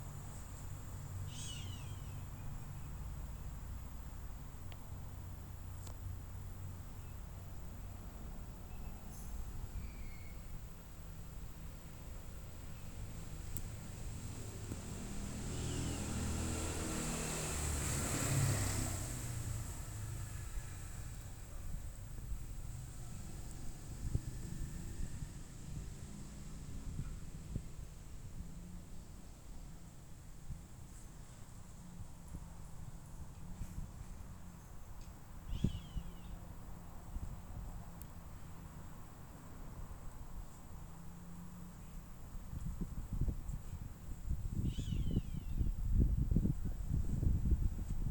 Roadside Hawk (Rupornis magnirostris)
Sex: Indistinguishable
Location or protected area: San Miguel de Tucumán
Condition: Wild
Certainty: Observed, Recorded vocal